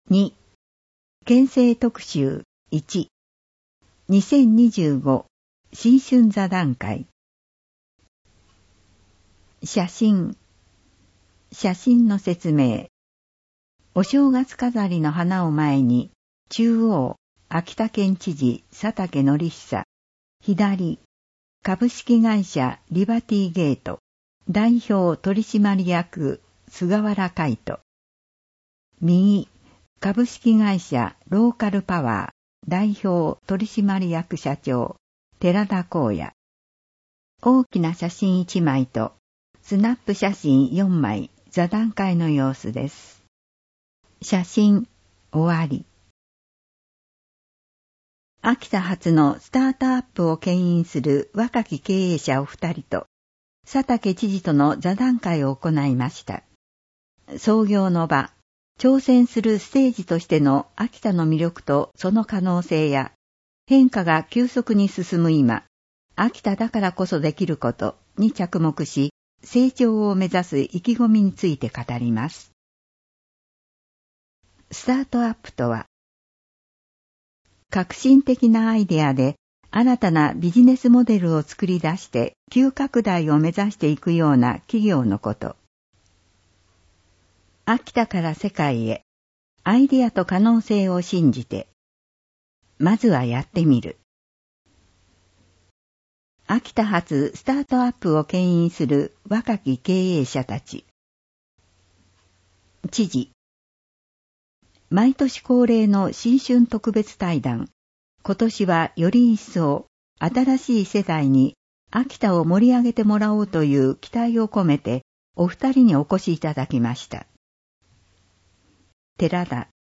02_特集_新春座談会.mp3